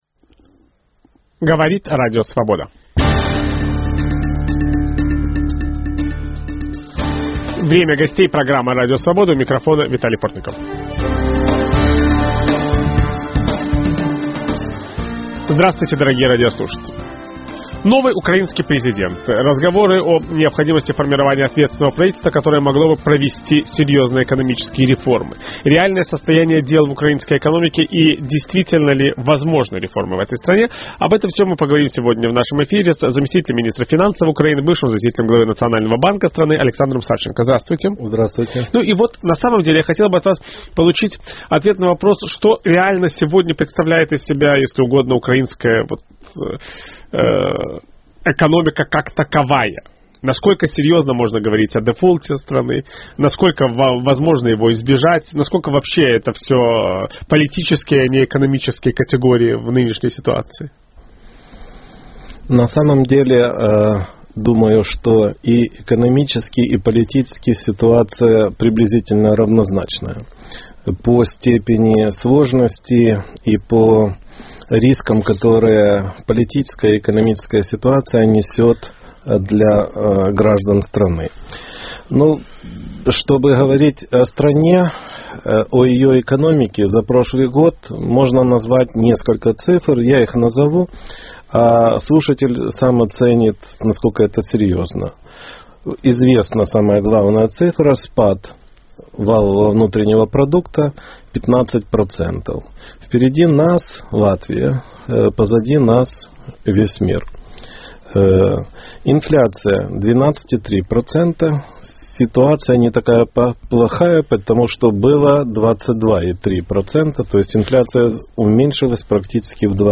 Какие реформы нужны украинской экономике? Виталий Портников беседует с заместителем министра финансов Украины, бывшим заместителем главы Национального банка страны Александром Савченко.